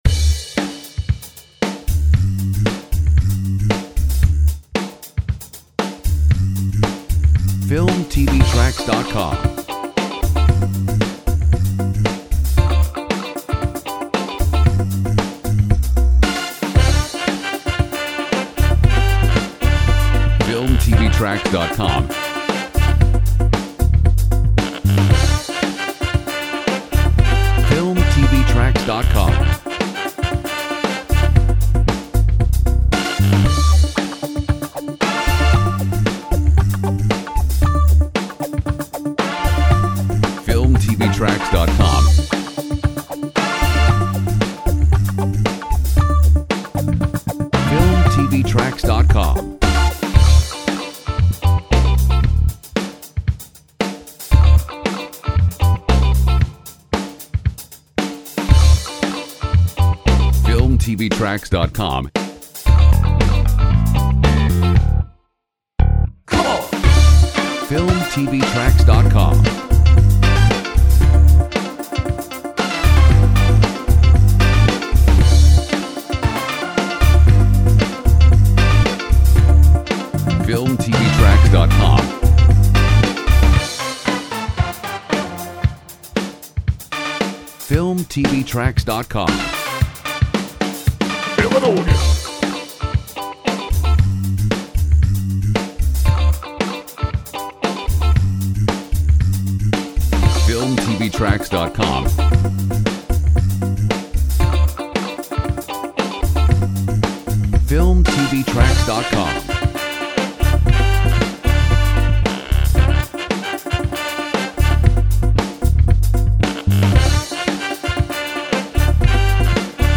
Funky Royalty free music